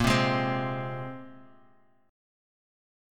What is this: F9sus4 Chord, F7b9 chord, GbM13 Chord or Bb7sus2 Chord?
Bb7sus2 Chord